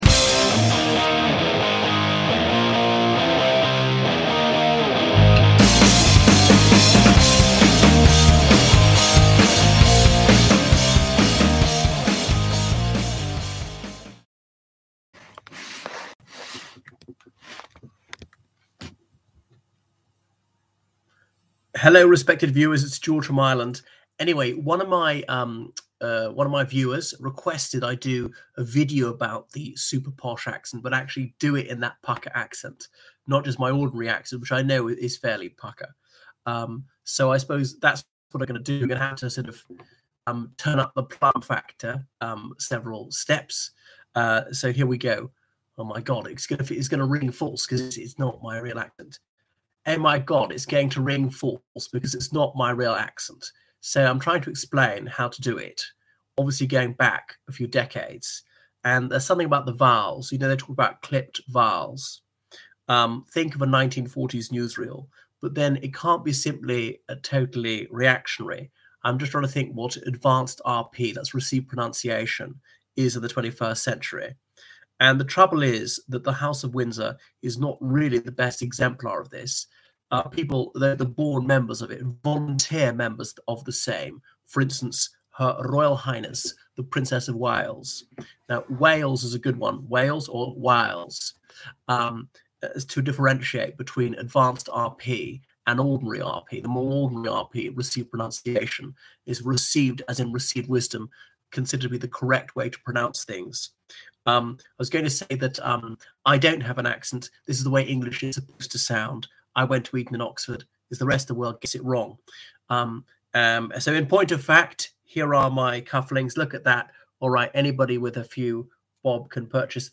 Podcast Episode 03672: Super posh accent – what is sounds like